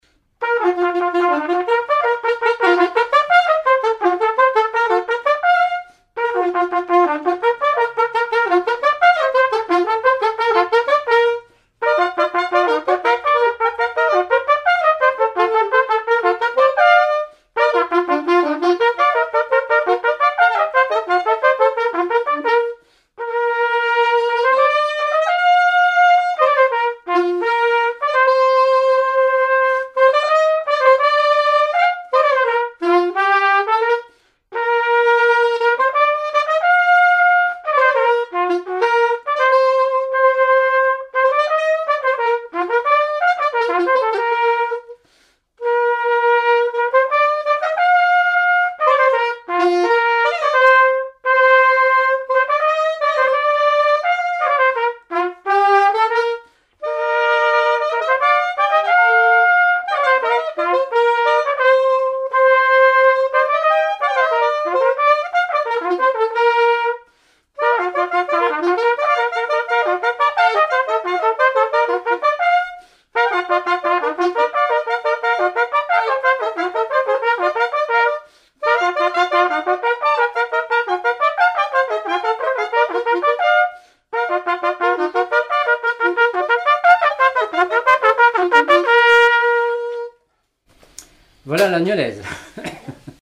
air de Nieul-sur-l'Autise
gestuel : à marcher
circonstance : fiançaille, noce
Pièce musicale inédite